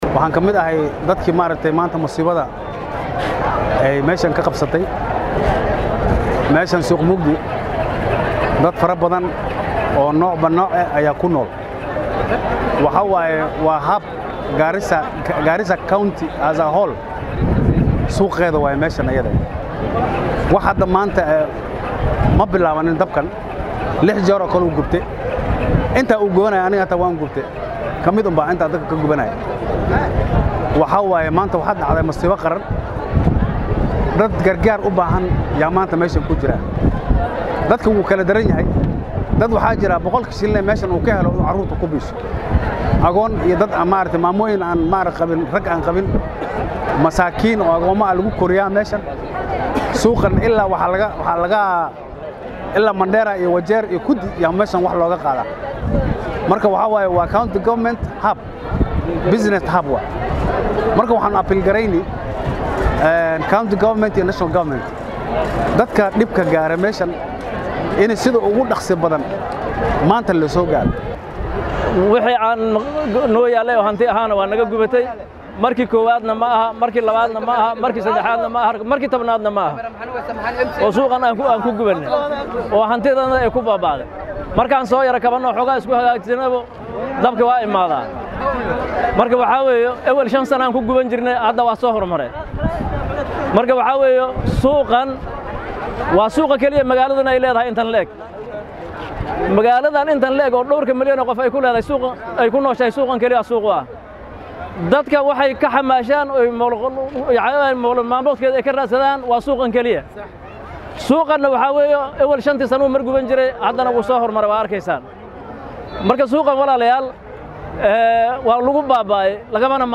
Dhacdadan ayaa waxaa ka hadlay ganacsatada uu saameeyay dabka.